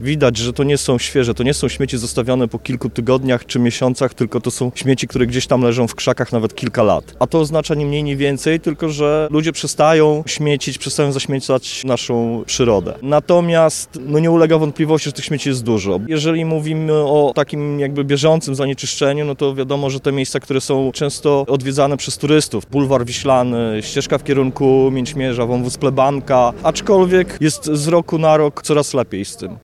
– Śmieci wciąż jest dużo, ale widać wzrost świadomości ekologicznej – mówi burmistrz Kazimierza Dolnego Artur Pomianowski.